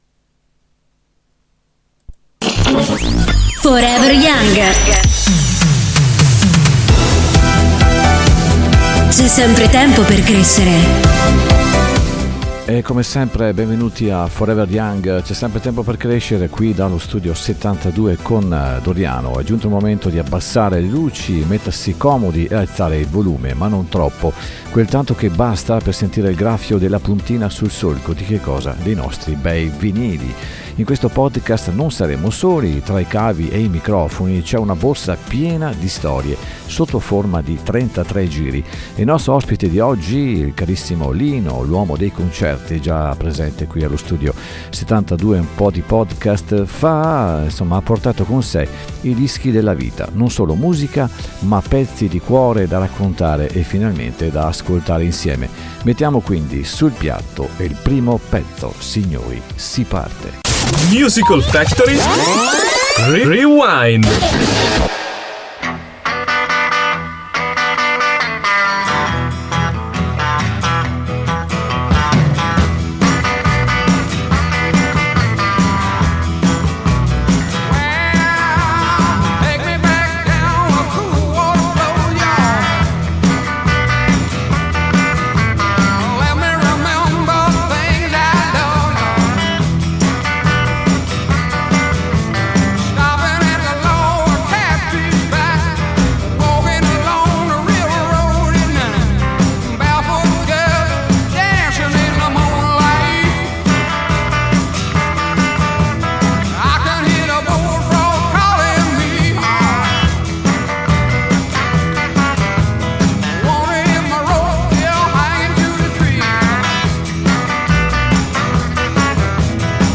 abbassate le luci, mettetevi comodi e alzate il volume, ma non troppo: quel tanto che basta per sentire il graffio della puntina sul solco. Oggi non siamo soli, tra i cavi e i microfoni c’è una borsa piena di storie sotto forma di 33 giri.